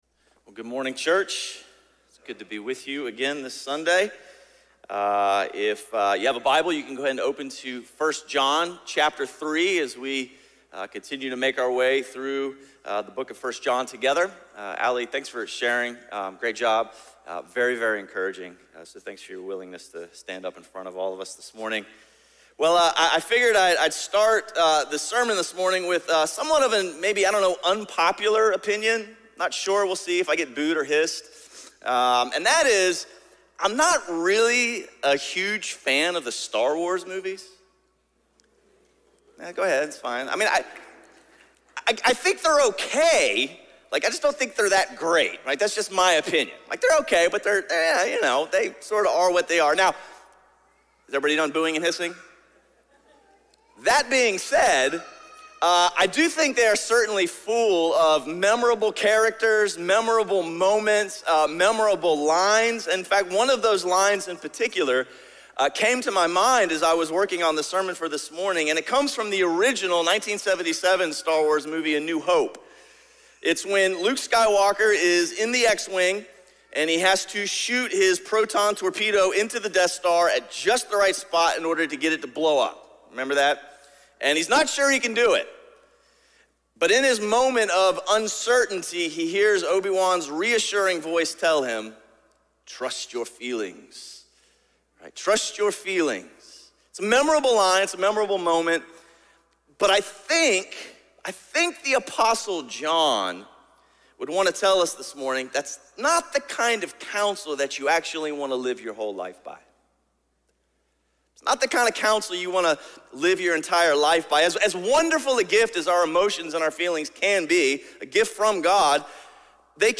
A message from the series "Life Under the Sun."